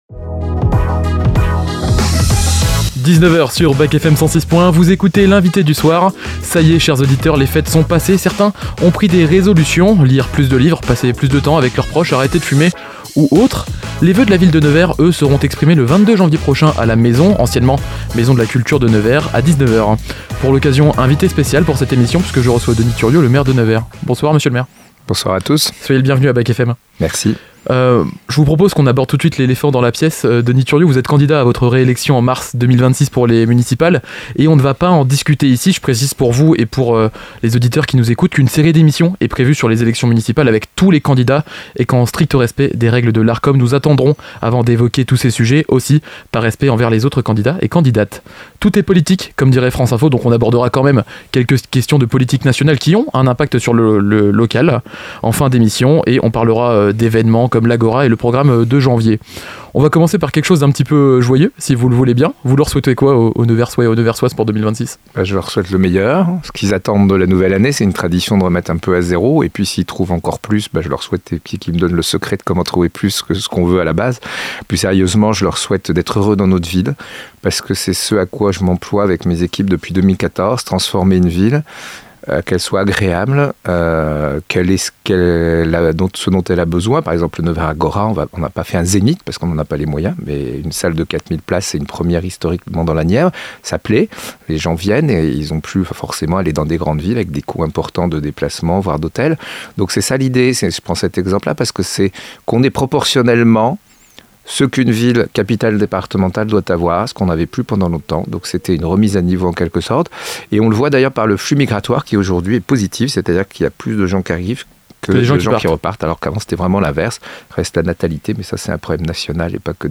Denis Thuriot, maire de Nevers et président de Nevers Agglomération est l’invité